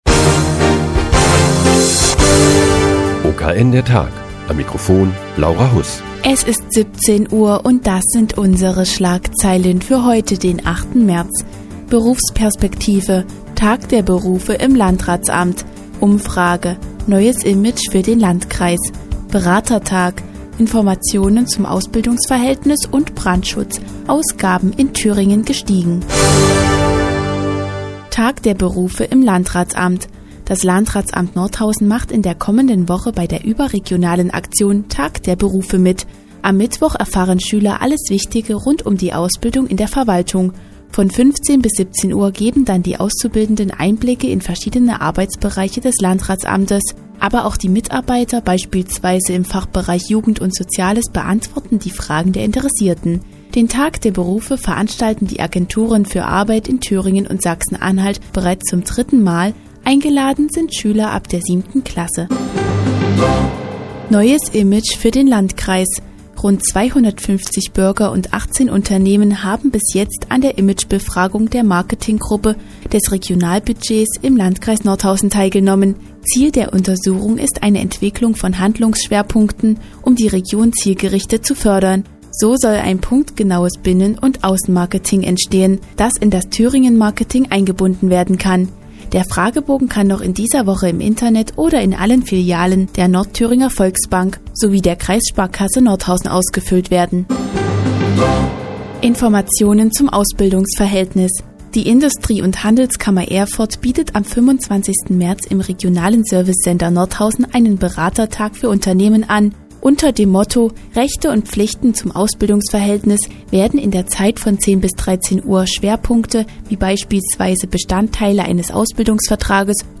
Die tägliche Nachrichtensendung des OKN ist nun auch in der nnz zu hören. Heute geht es um den "Tag der Berufe" im Landratsamt und eine Befragung zum Image des Landkreises Nordhausen.